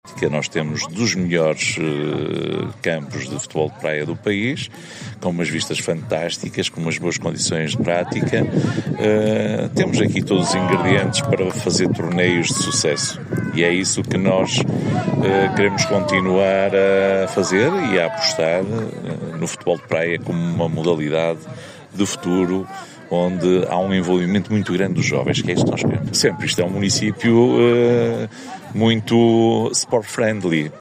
Também o anfitrião, esteve presente, Benjamim Rodrigues, presidente da câmara de Macedo, enaltecendo as infra-estruturas do campo de jogos: